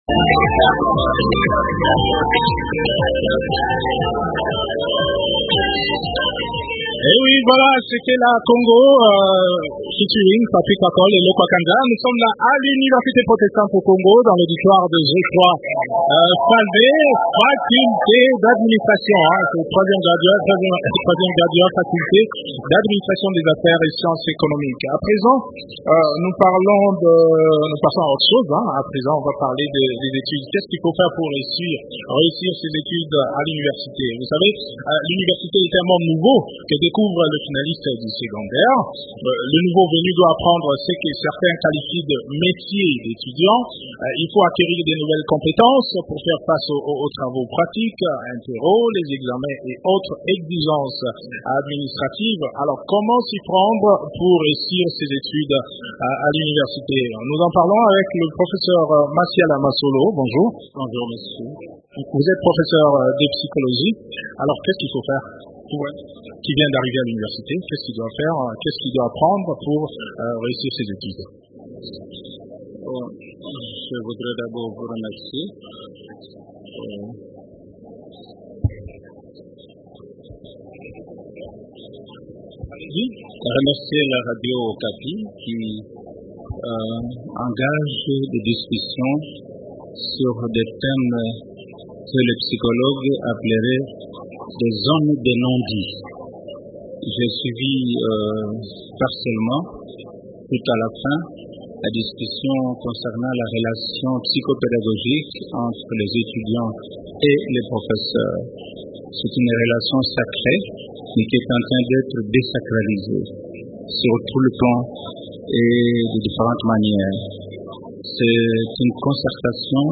Les éléments de réponse dans cet entretien